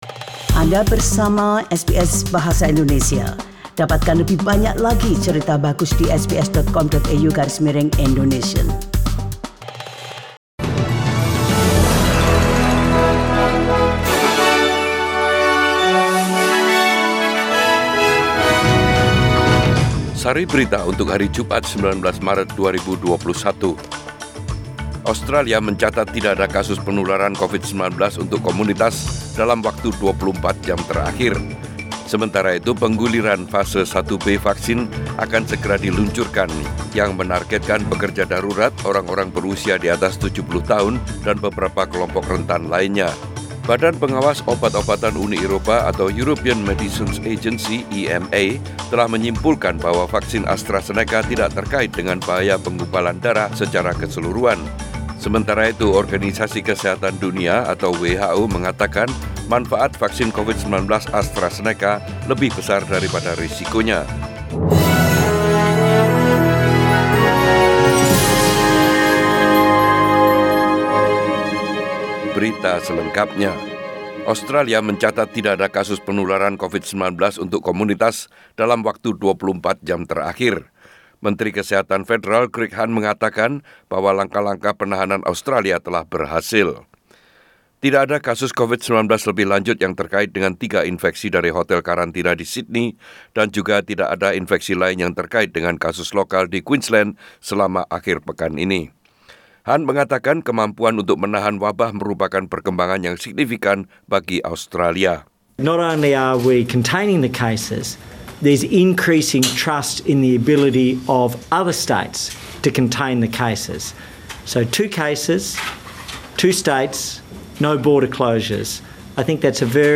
SBS Radio News in Bahasa Indonesia - 19 March 2021